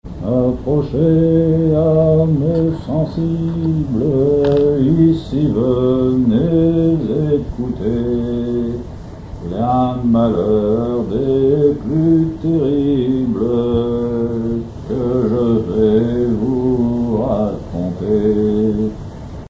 Chansons maritimes
Pièce musicale inédite